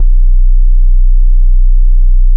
TEST BASS -L.wav